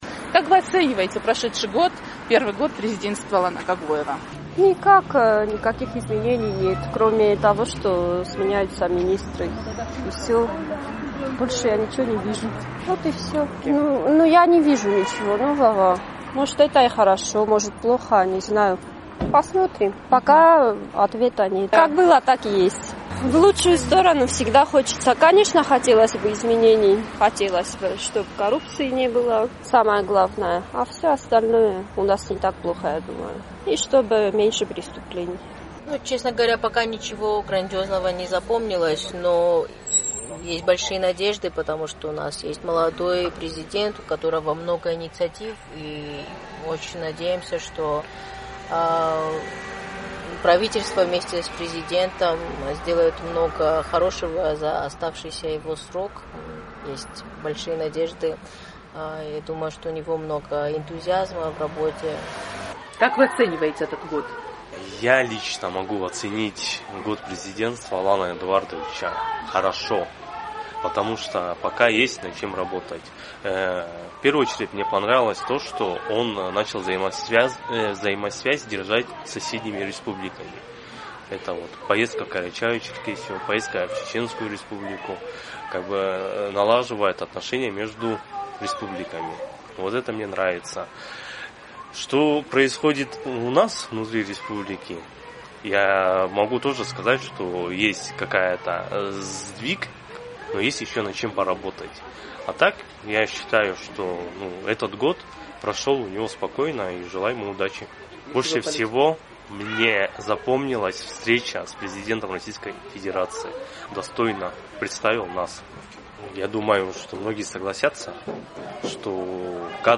24 мая исполнился год со вступления в должность президента Алана Гаглоева. «Эхо Кавказа» спросило у цхинвальцев, как они его оценивают и чем им запомнился прошедший год.